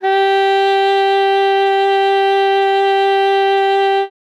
42c-sax07-g4.wav